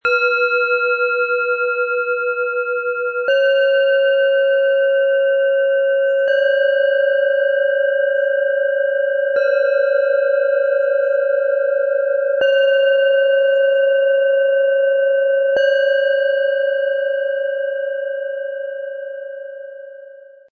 Erfahre Geborgenheit, Herzöffnung und entwickle Ausdruckskraft - Dein Klangweg zu dir - Set aus 3 Planetenschalen, Ø 10,5 -11,0 cm 0,81 kg
Geborgenheit und Sicherheit - Tiefster Ton: Mond
Öffne dein Herz und wende dich der Welt zu - Mittlerer Ton: Jahreston OM
Kommunikation und Denken - Höchster Ton: Merkur
Dank unseres Sound-Player - Jetzt reinhörens können Sie den echten Klang dieser speziellen Schalen des Sets selbst anhören.
Der gratis Klöppel lässt die Schalen wohltuend erklingen.
Bengalen Schale, Matt, 10,7 cm Durchmesser, 5,3 cm Höhe